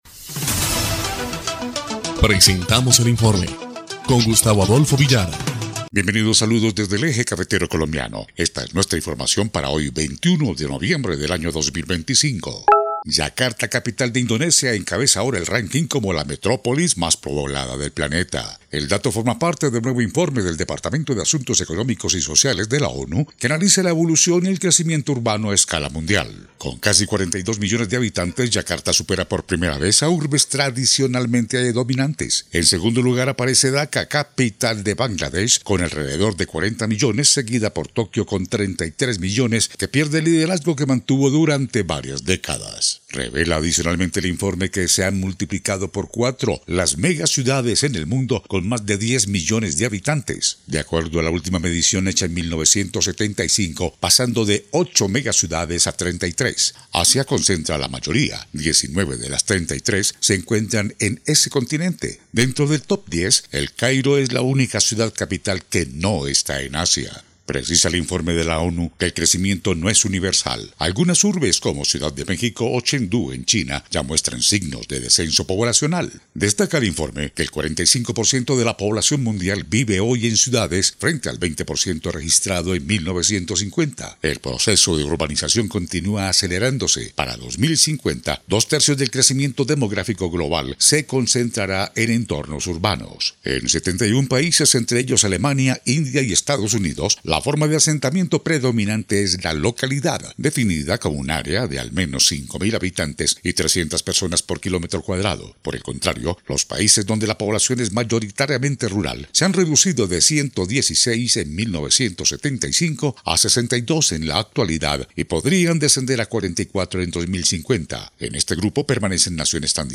EL INFORME 2° Clip de Noticias del 21 de noviembre de 2025